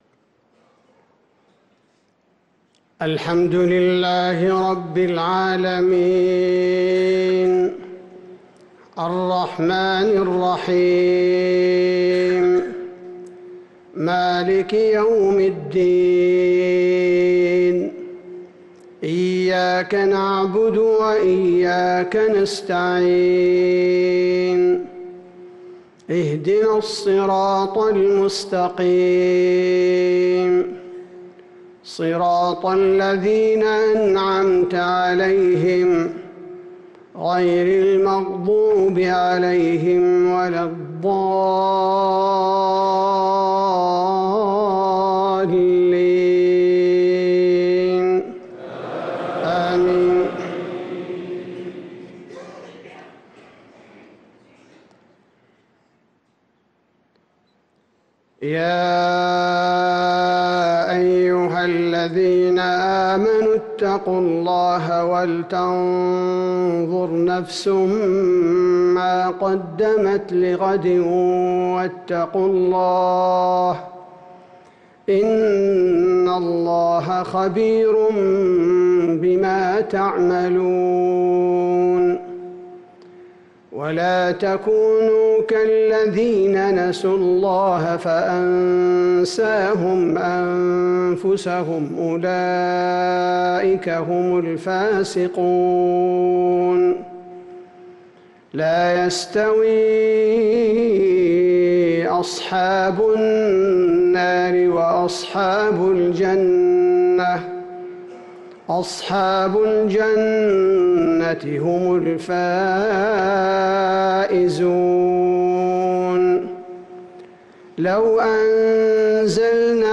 صلاة العشاء للقارئ عبدالباري الثبيتي 29 جمادي الأول 1445 هـ
تِلَاوَات الْحَرَمَيْن .